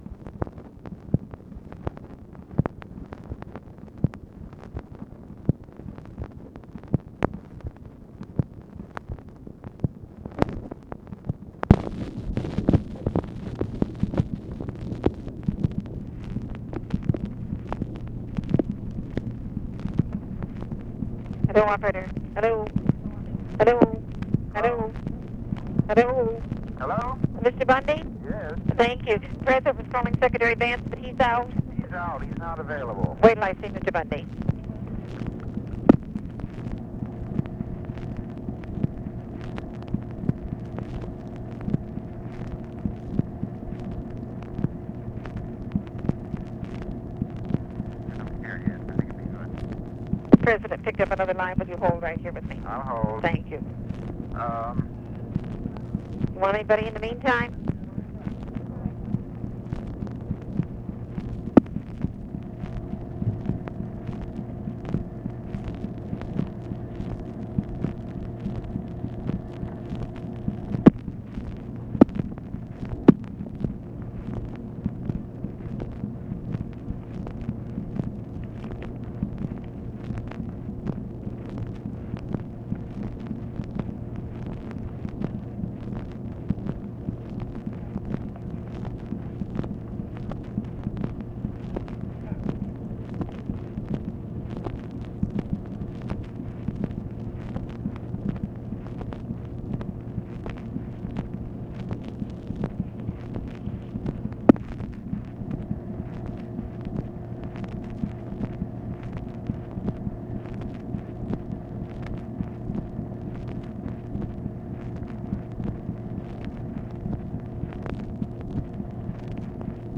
Conversation with MCGEORGE BUNDY, TELEPHONE OPERATOR, OFFICE CONVERSATION and ABE FORTAS, May 17, 1965
Secret White House Tapes